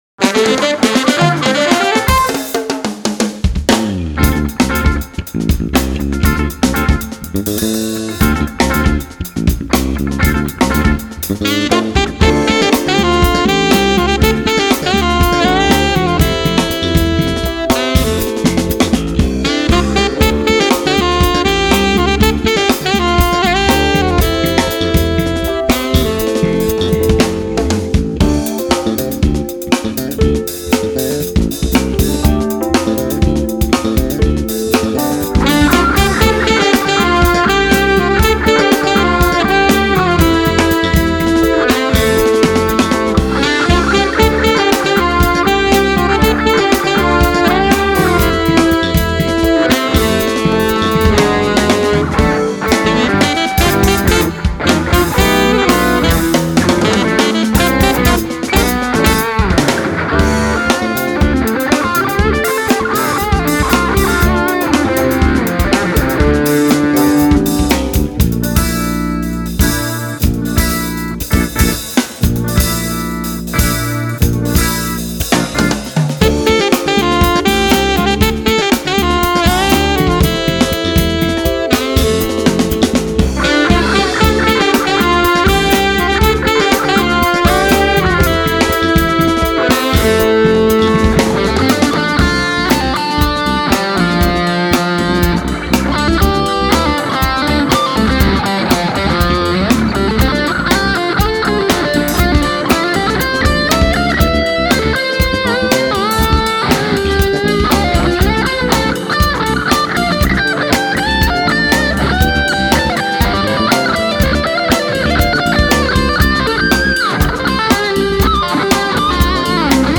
Fusion jazz cd
saxophones
guitar
keyboards-piano
bass
drums